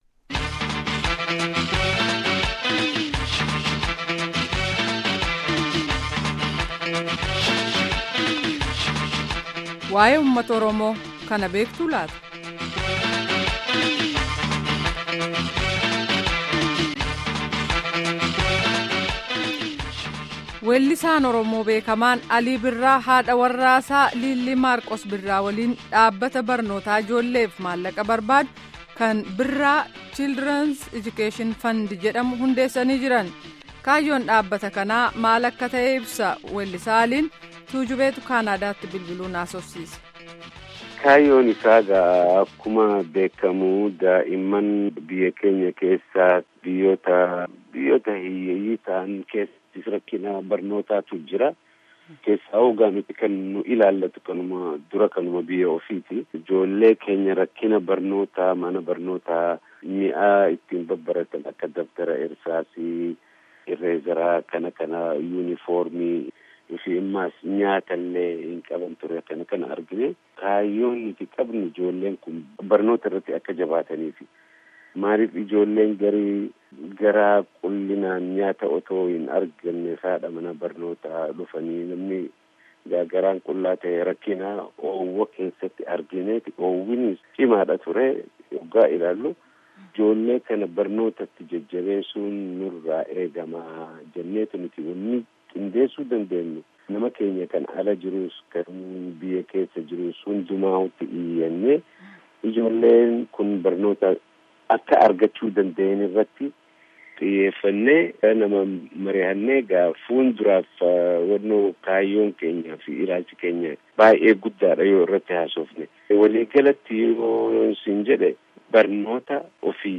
Gaaffii fi deebii dhaggeeffadhaa